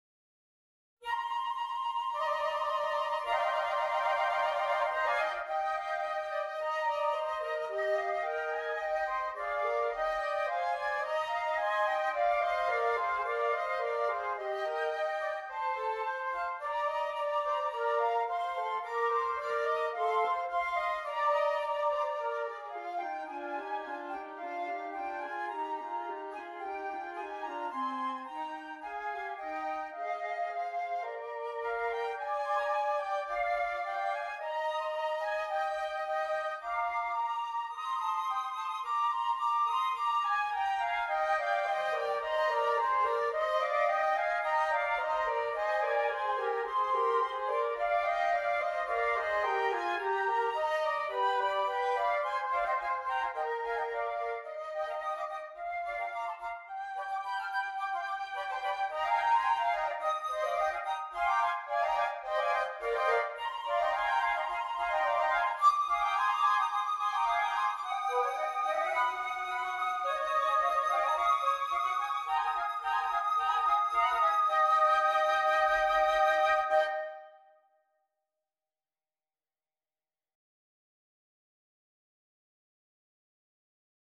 3 Flutes
Trills and frills abound in this decorativearrangement